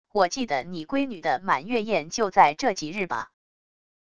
我记得你闺女的满月宴就在这几日吧wav音频生成系统WAV Audio Player